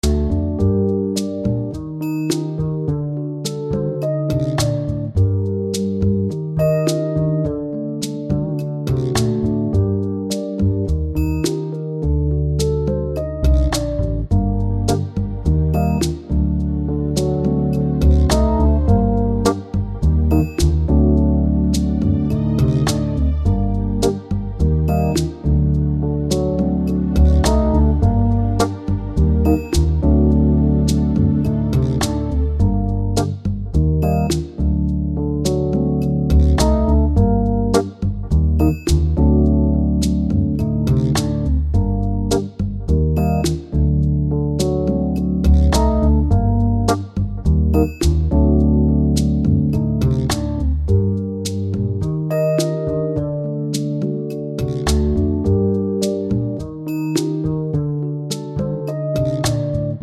Acoustic Version with No Backing Vocals